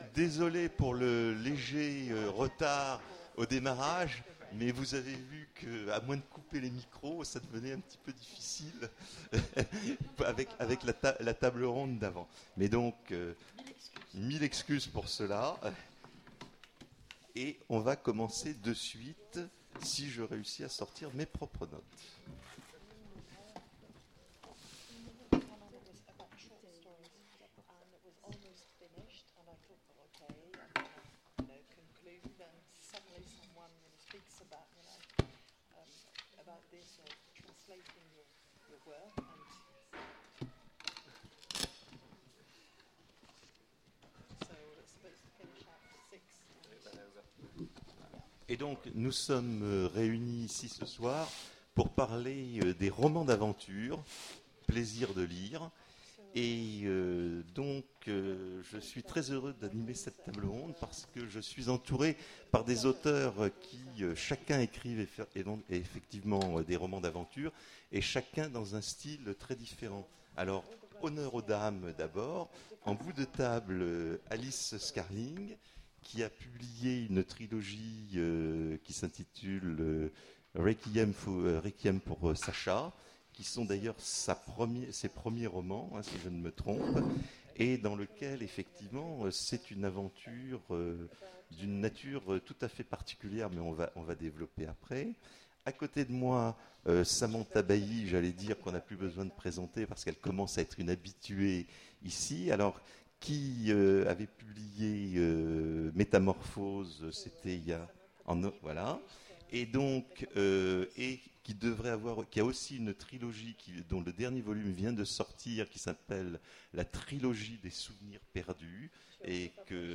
Imaginales 2015 : Conférence Romans d'aventure